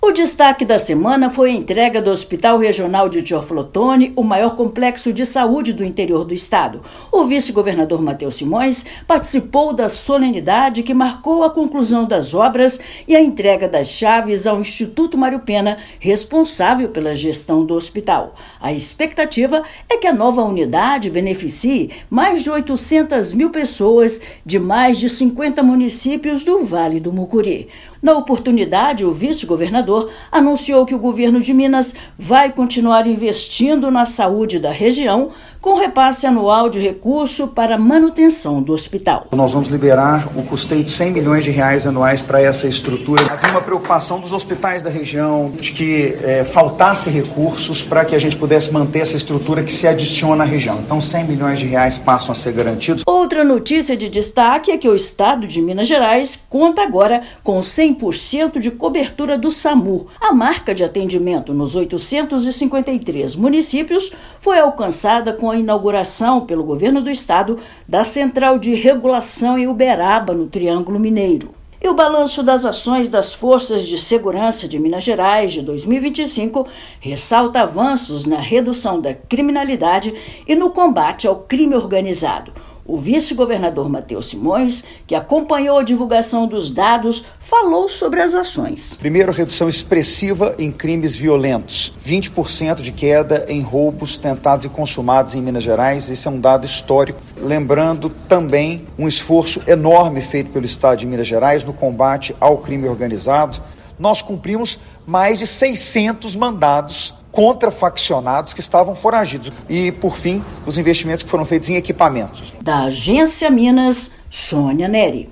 O noticiário aborda ainda balanço das Forças de Segurança de Minas, com avanços no combate à criminalidade e ao crime organizado.